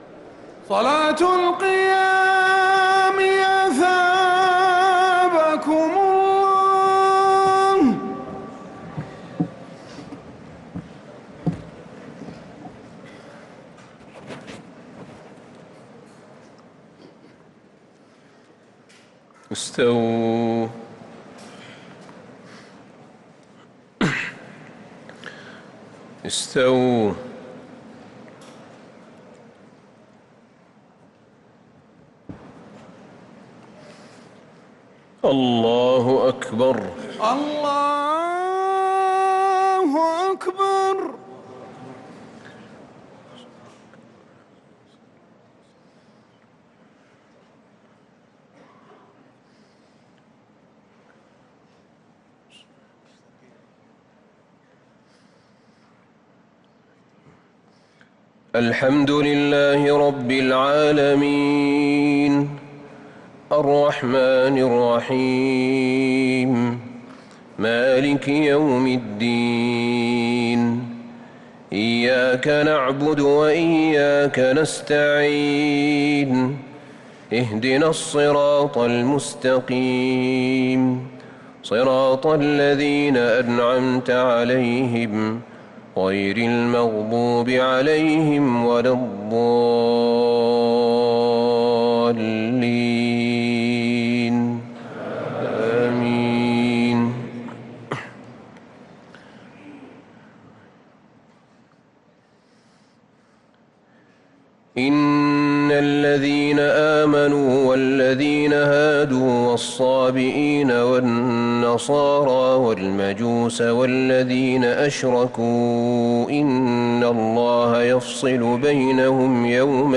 صلاة التراويح ليلة 22 رمضان 1443 للقارئ أحمد بن طالب حميد - الثلاث التسليمات الأولى صلاة التراويح